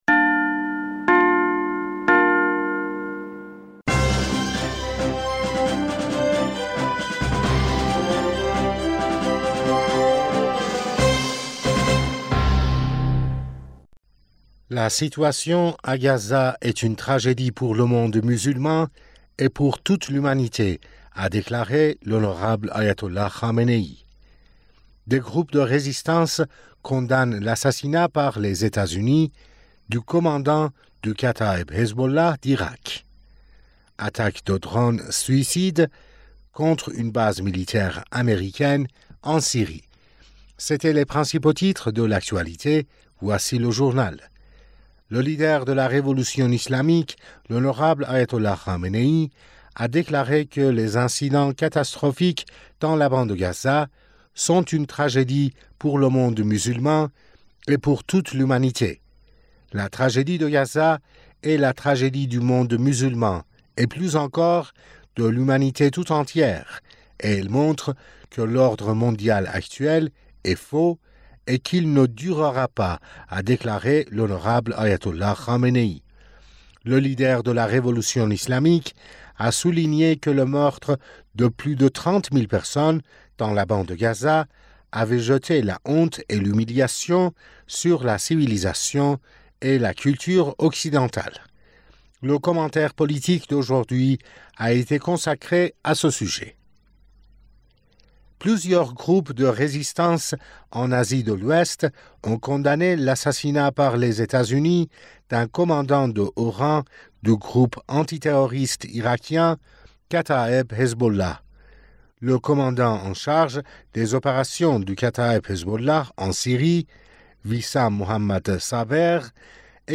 Bulletin d'information du 08 Fevrier 2024